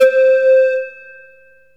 SYNTH GENERAL-1 0012.wav